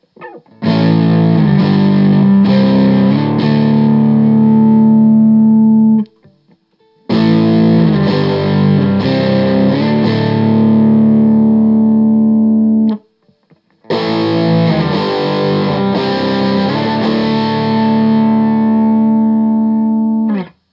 Barre Chords #2
As usual, for these recordings I used my normal Axe-FX Ultra setup through the QSC K12 speaker recorded into my trusty Olympus LS-10.
For each recording I cycle through the neck pickup, both pickups, and finally the bridge pickup.
A guitar like this is really about that semi-hollow sound, and it delivers that in every position and on every fret, though that sort of hollow timbre can obviously get lost when the gain is up high and the effects are set to overwhelming.